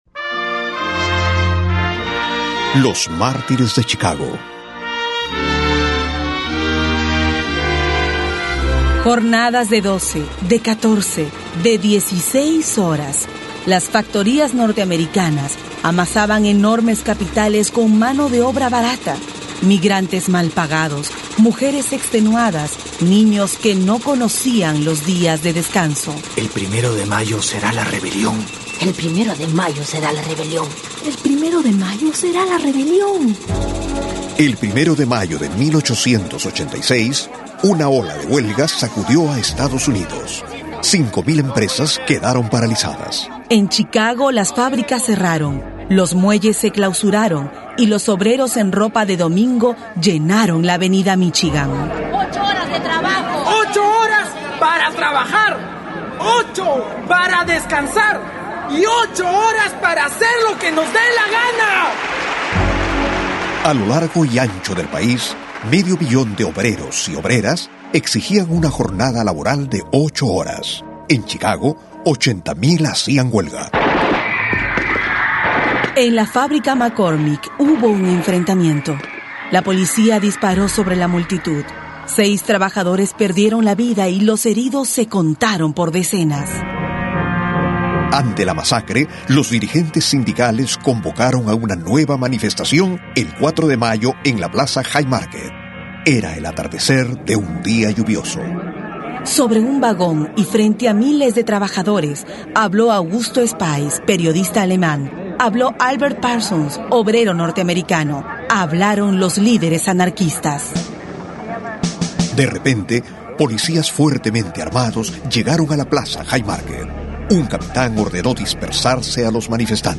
MÁRTIRES DE CHICAGO - radioteatro
RADIO CENTRAL, presenta el radioteatro: "MÁRTIRES DE CHICAGO"